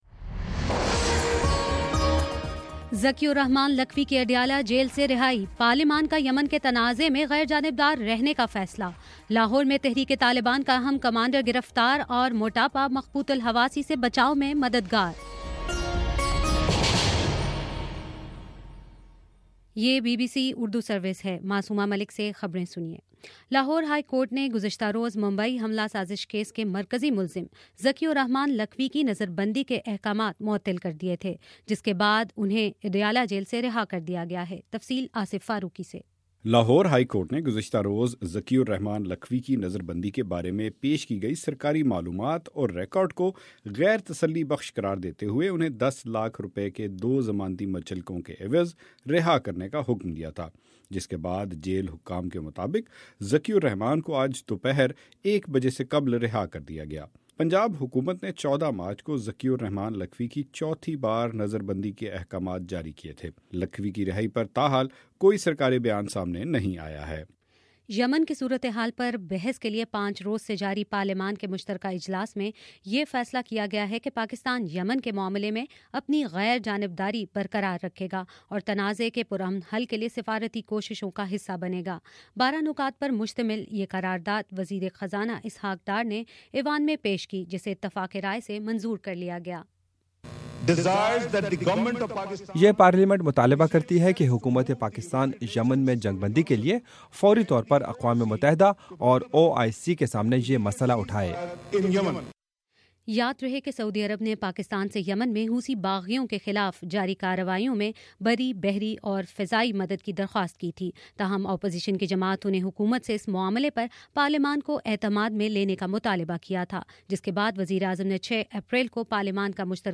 اپریل 10: شام پانچ بجے کا نیوز بُلیٹن